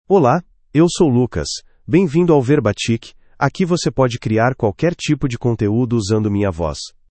Lucas — Male Portuguese (Brazil) AI Voice | TTS, Voice Cloning & Video | Verbatik AI
MalePortuguese (Brazil)
Voice sample
Listen to Lucas's male Portuguese voice.
Lucas delivers clear pronunciation with authentic Brazil Portuguese intonation, making your content sound professionally produced.